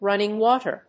アクセントrúnning wáter